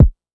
808 Mafia Kick.wav